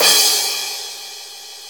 CM CYMS 7.wav